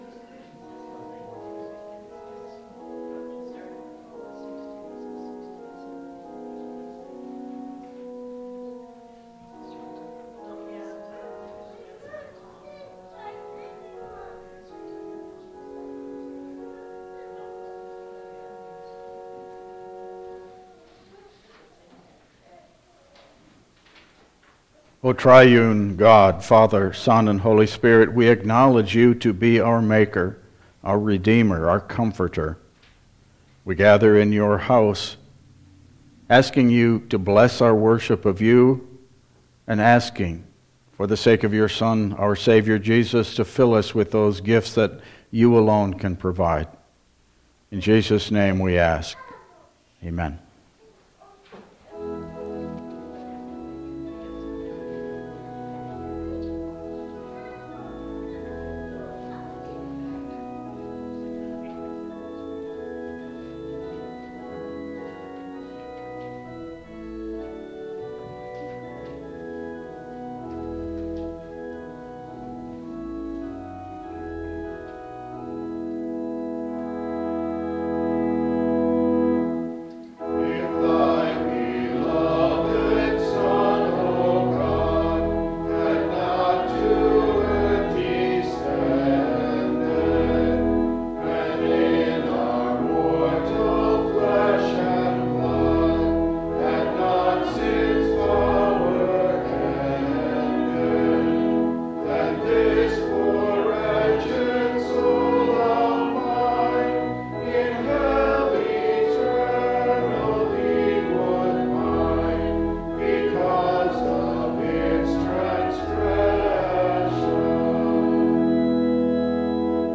Passage: Isaiah 55:1-5 Service Type: Regular Service